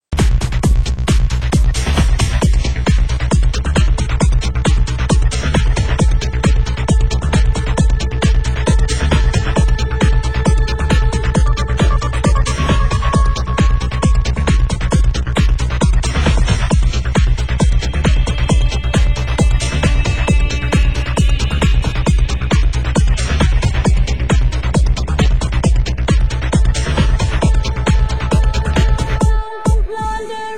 Genre: Trance
Genre: UK House